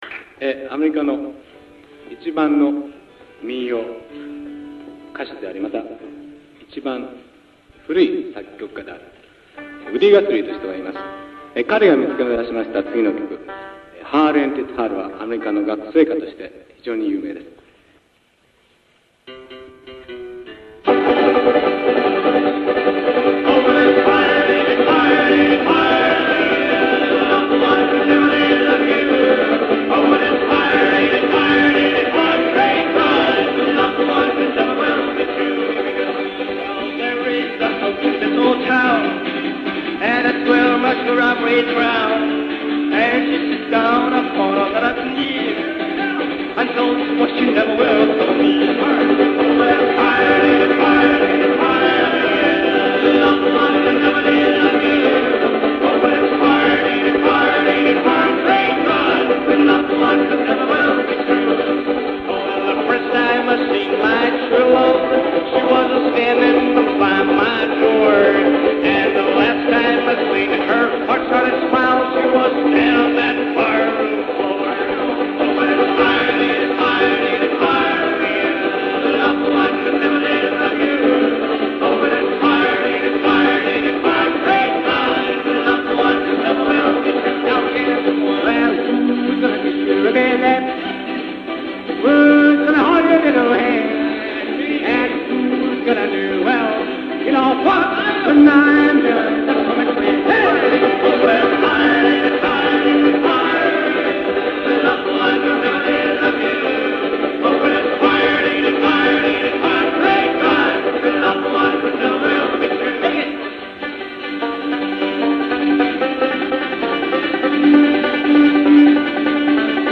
Japanese folk groups